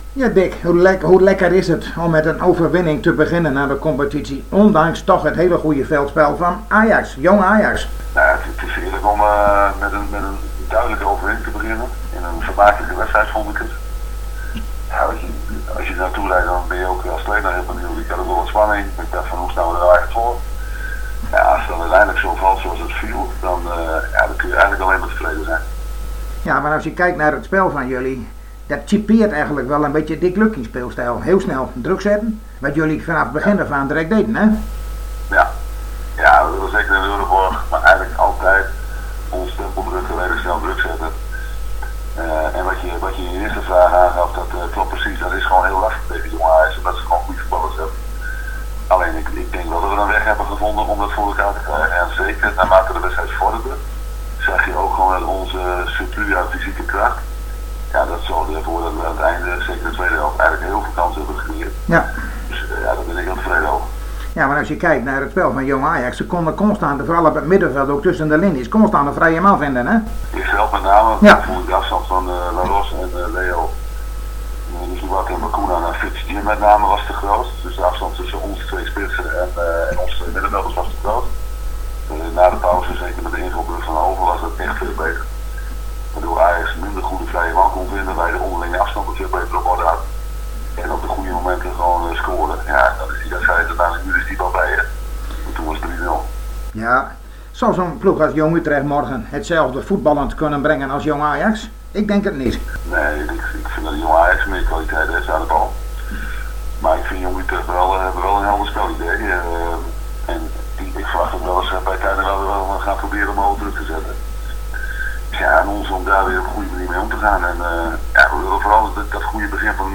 Wij spraken zojuist met trainer Dick Lukkien over de wedstrijd van FC Groningen morgen in De Galgenwaard tegen Jong FC Utrecht.